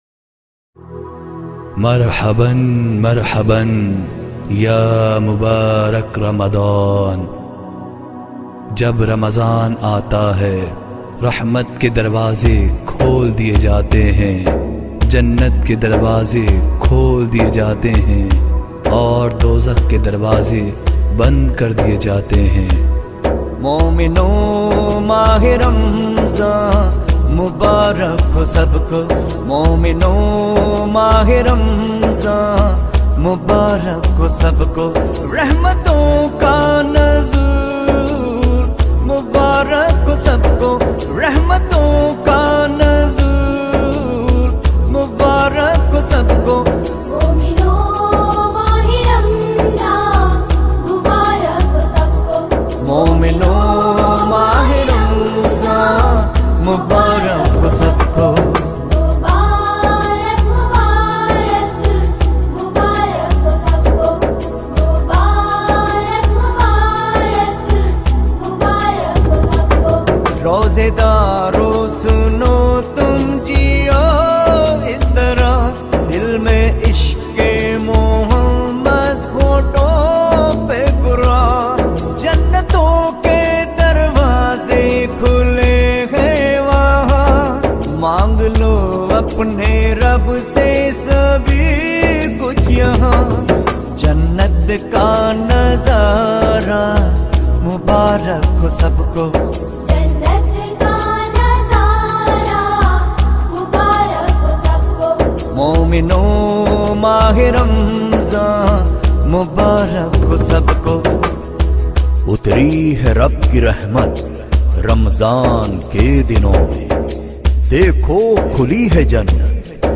Download Naats, Qawwali, and Islamic Resources.
has been blessed with a soothing and calm voice